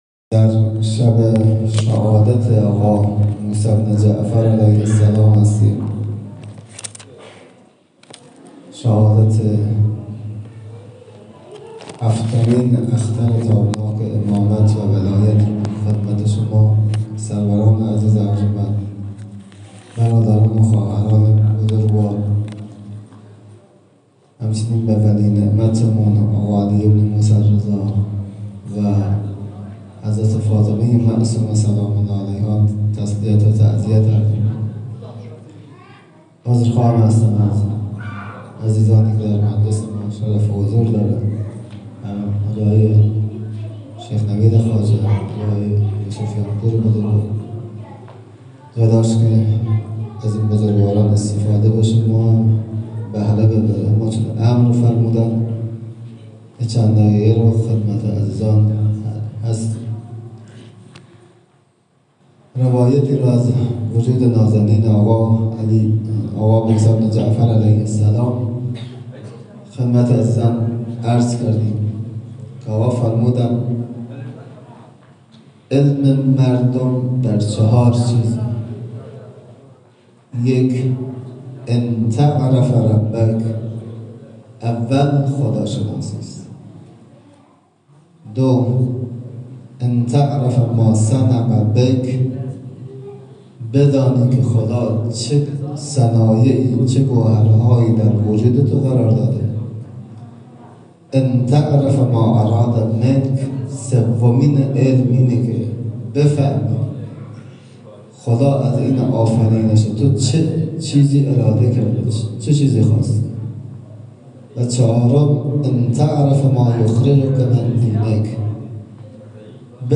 شهادت امام کاظم(علیه السلام)26بهمن1401-هیئت میثاق الحسین (ع) سیستان